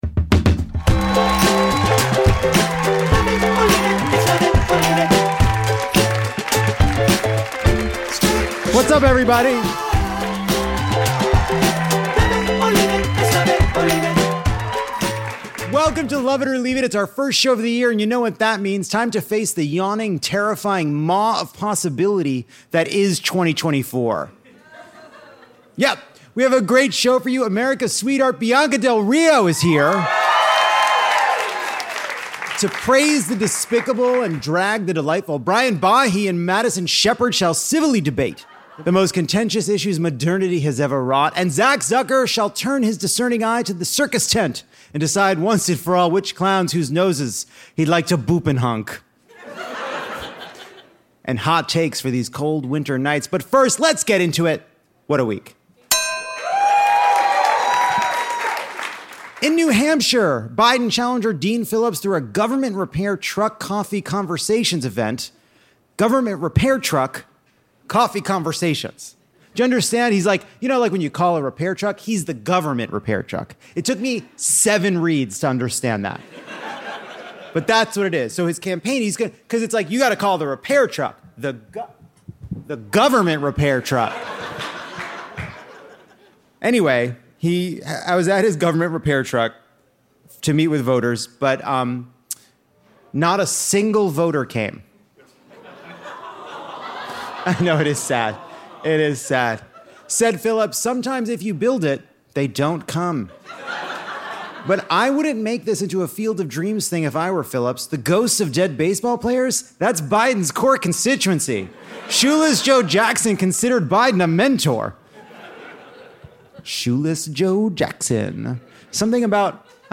Lovett or Leave It is here to keep you warm on these long, cold January nights with a brand-new episode, live from The Lodge Room in Los Angeles.